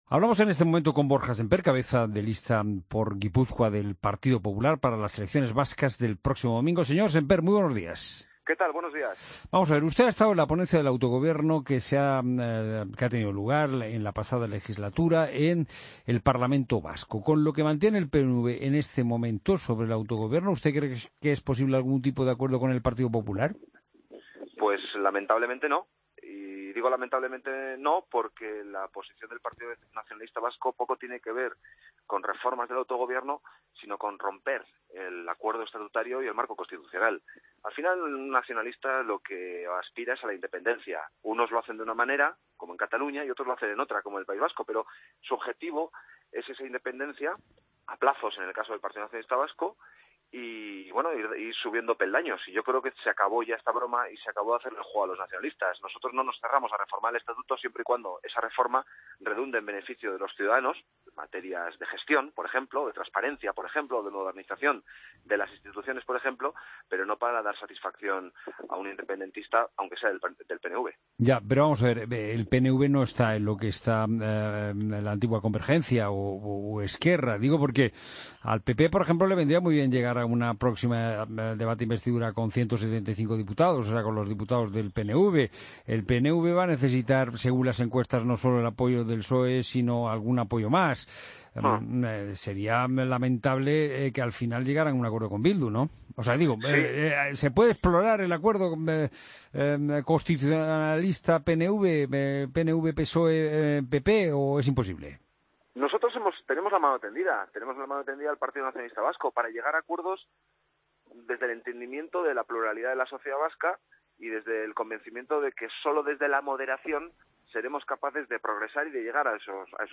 Escucha al entrevista a Borja Semper en La Mañana de Fin de Semana